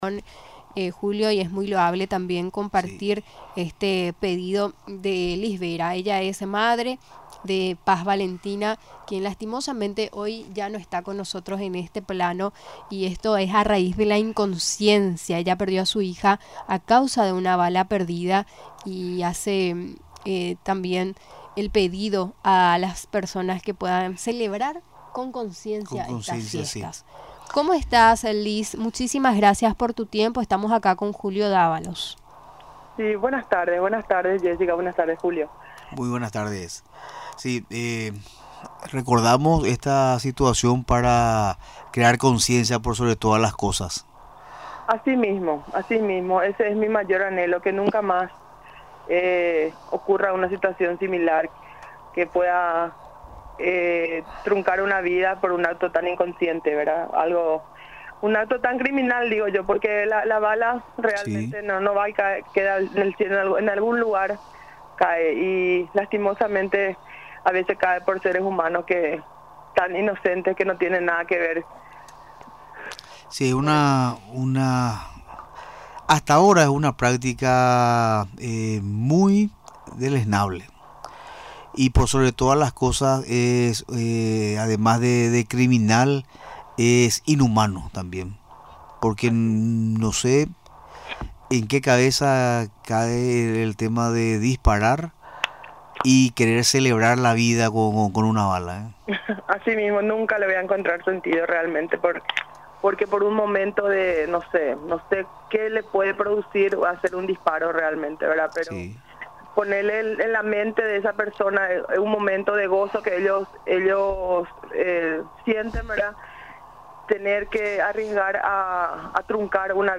Durante la entrevista en Radio Nacional del Paraguay, recordó que desde aquel año, no descansa en la campaña de concienciar sobre la manipulación de las armas y disparar al aire.